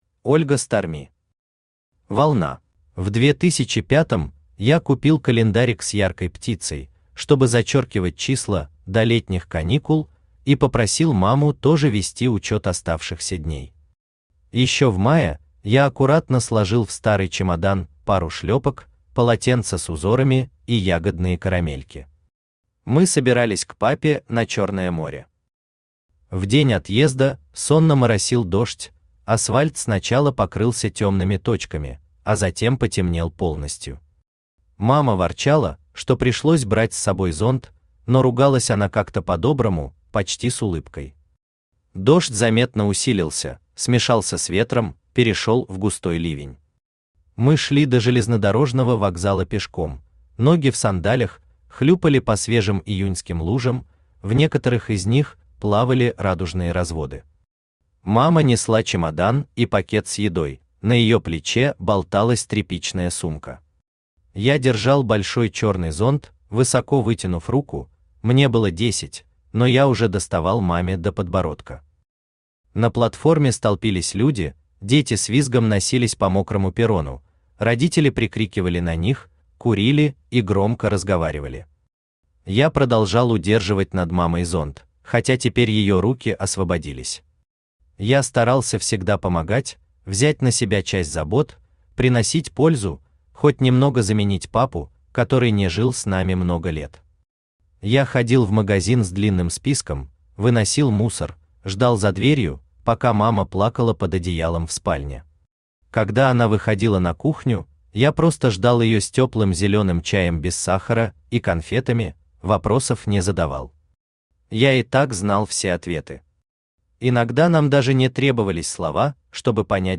Aудиокнига Волна Автор Ольга Сторми Читает аудиокнигу Авточтец ЛитРес.